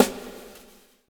SND DRUMAD-R.wav